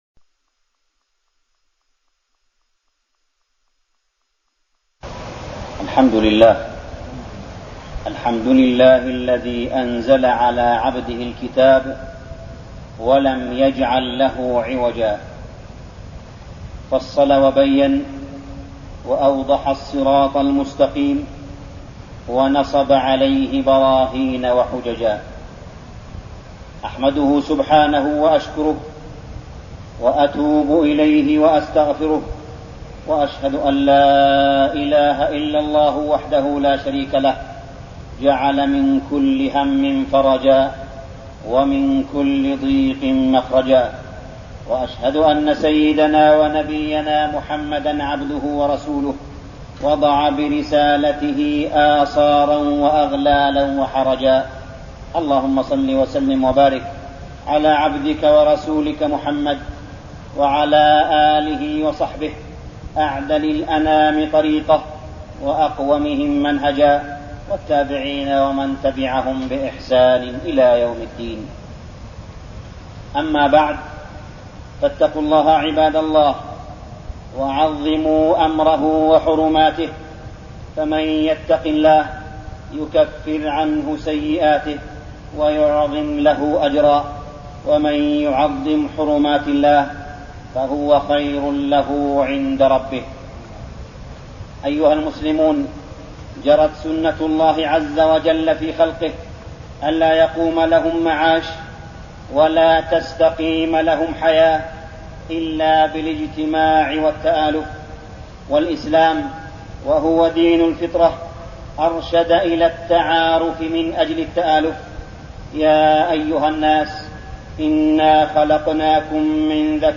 خطبة الإستسقاء 6-5-1410هـ > خطب الاستسقاء 🕋 > المزيد - تلاوات الحرمين